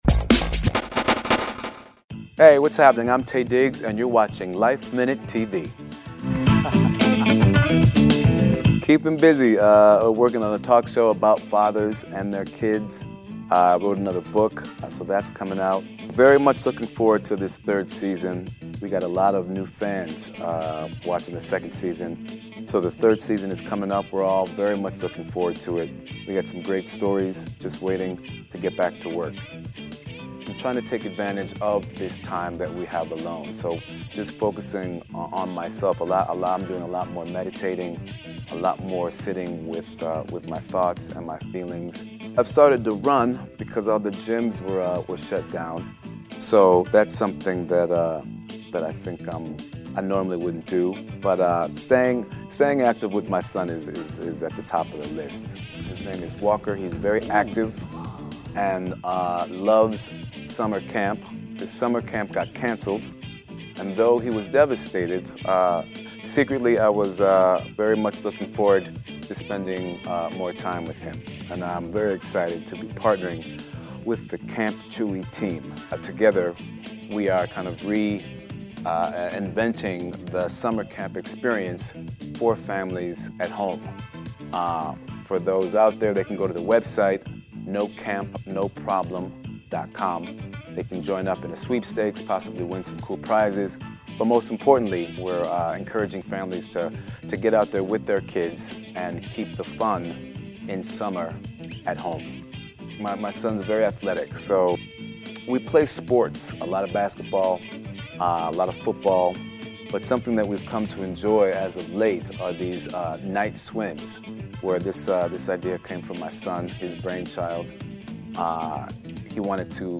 We sat down with the star to learn all about how he and his 10-year old son are recreating the summer camp experience at home this year.